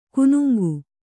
♪ kunuŋgu